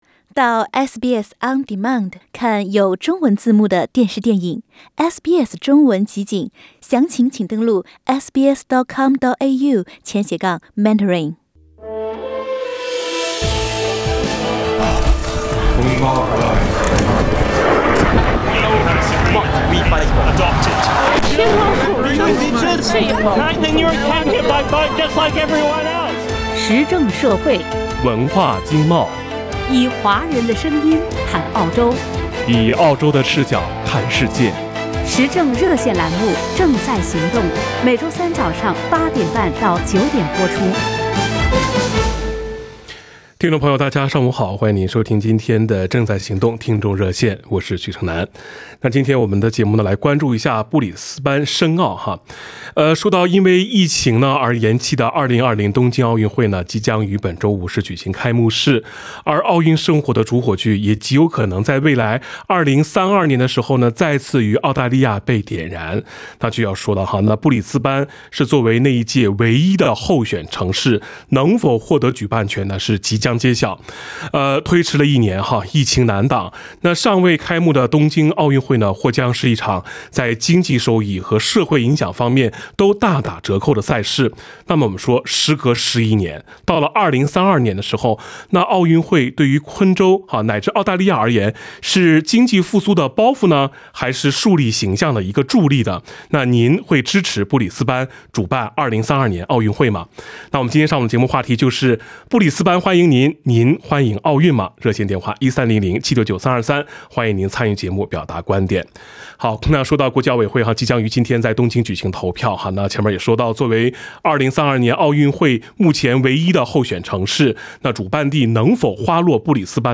（点击图片，收听热线回放）